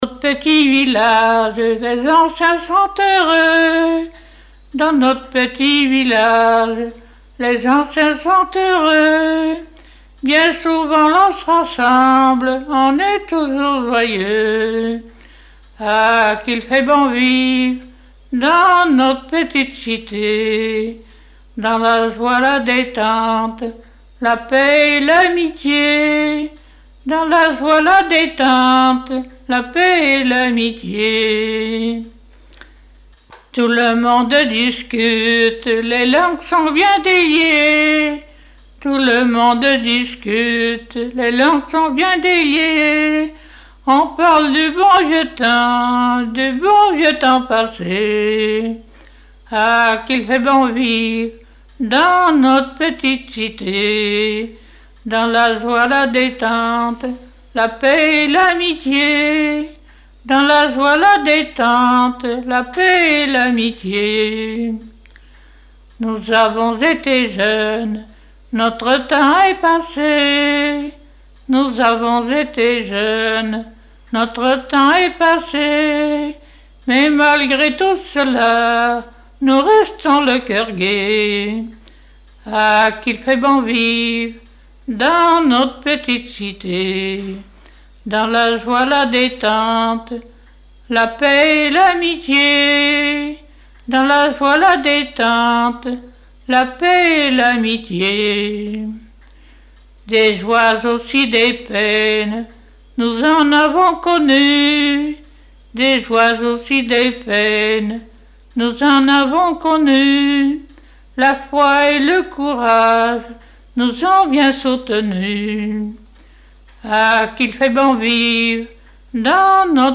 Mémoires et Patrimoines vivants - RaddO est une base de données d'archives iconographiques et sonores.
Pièce musicale inédite